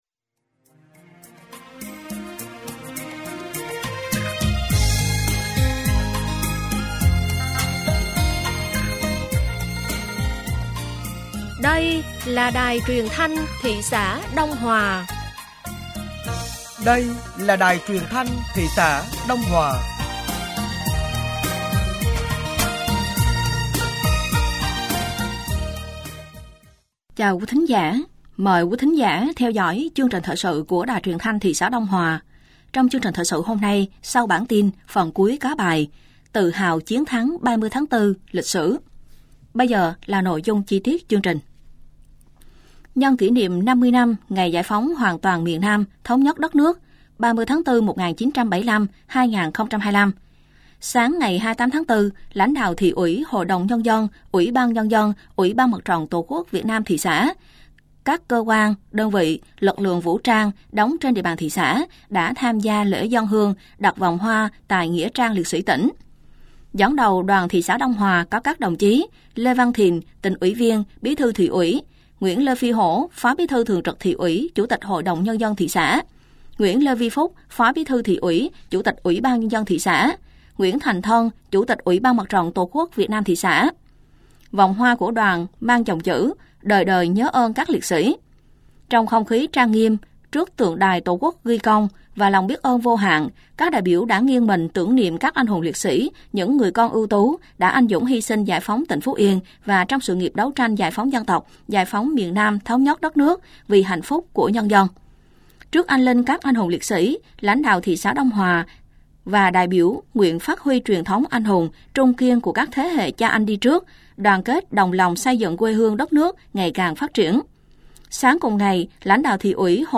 Thời sự tối ngày 28 và sáng ngày 29 tháng 4 năm 2025